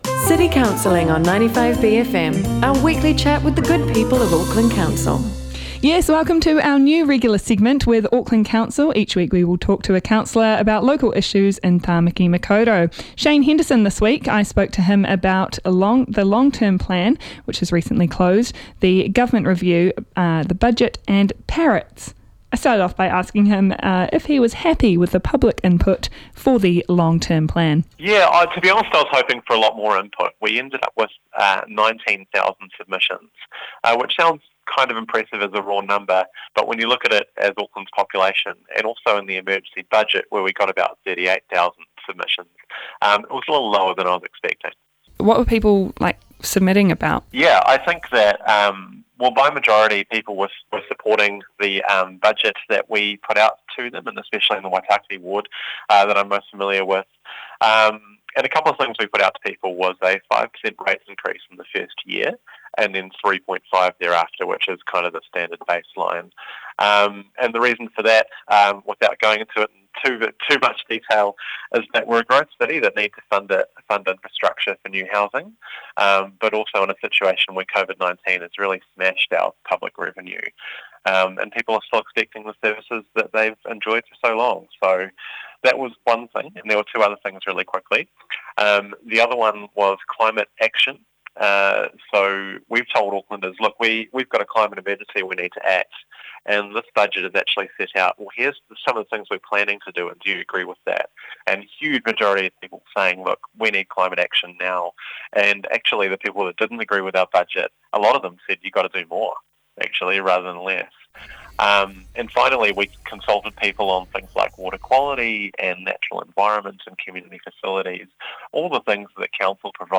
speaks to Waitākere Councillor Shane Henderson